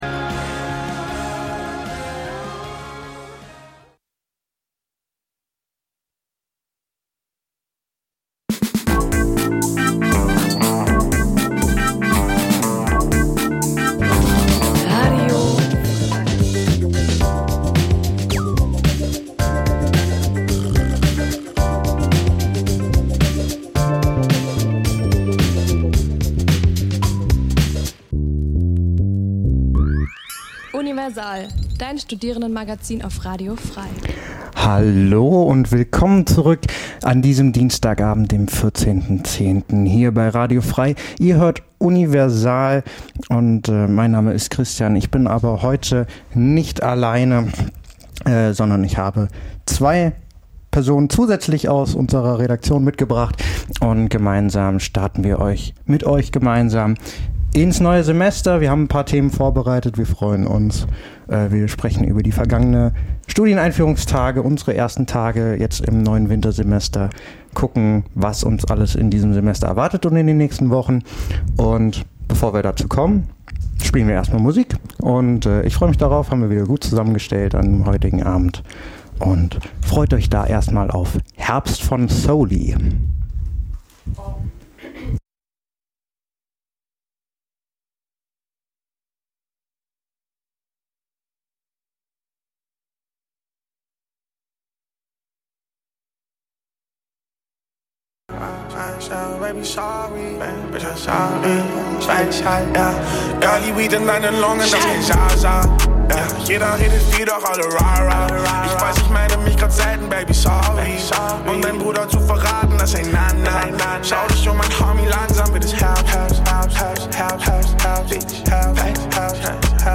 Die Sendungen werden gemeinsam vorbereitet - die Beitr�ge werden live im Studio pr�sentiert.
Studentisches Magazin Dein Browser kann kein HTML5-Audio.